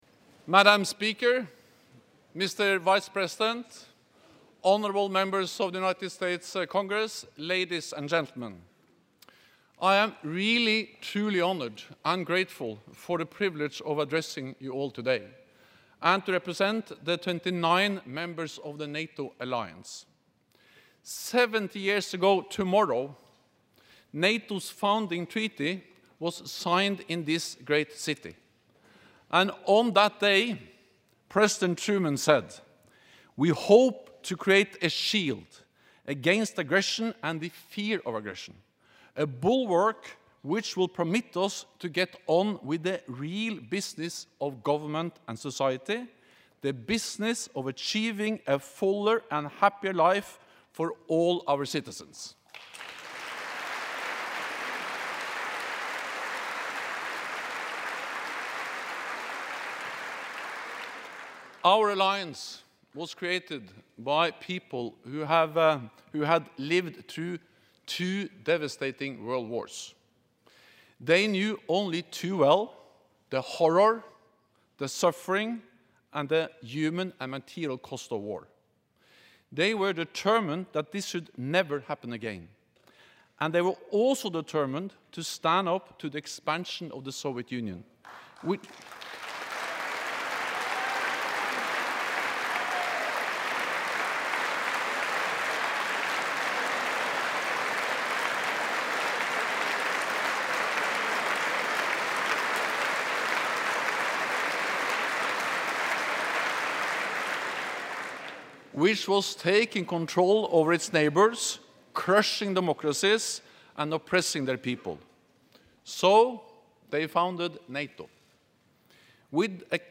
Address to the United States Congress by NATO Secretary General Jens Stoltenberg